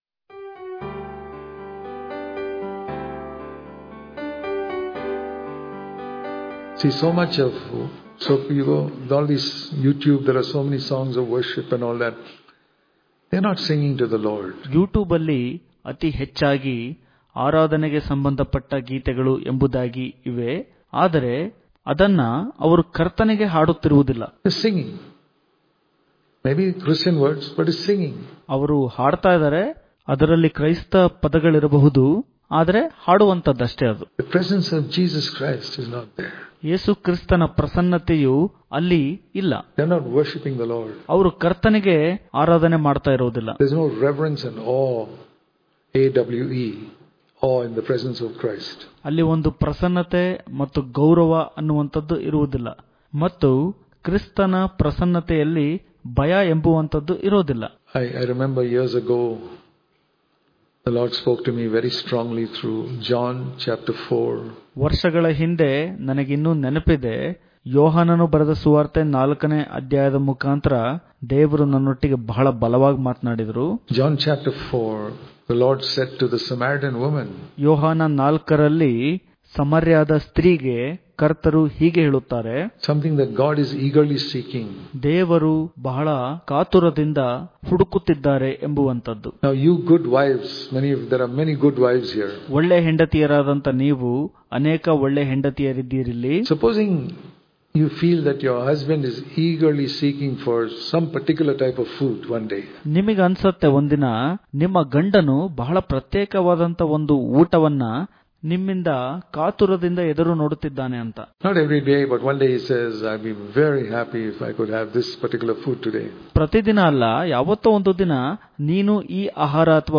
March 20 | Kannada Daily Devotion | The Father Is Eagerly Seeking For Worshipers Daily Devotions